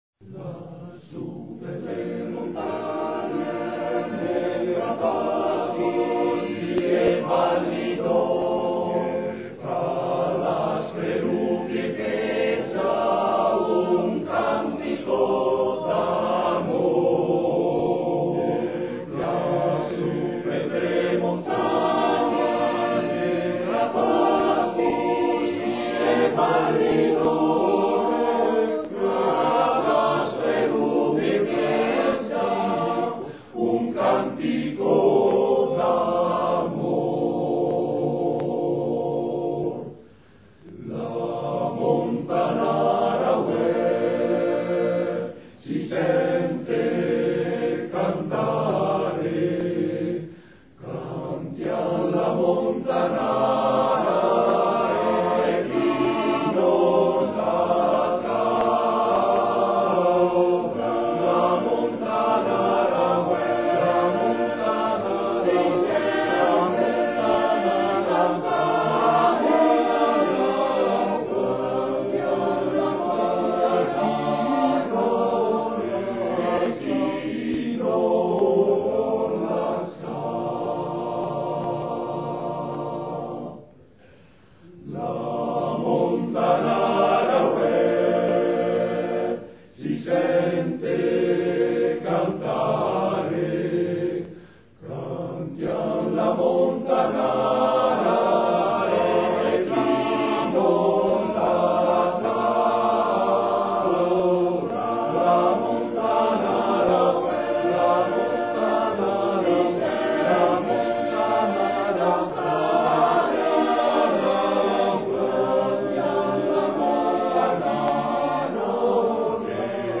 Documento della cerimonia di intitolazione del centro mariapoli di Cadine a Chiara Lubich - 24 gennaio 2009
Coro Dolomiti: “Fratello sole, sorella luna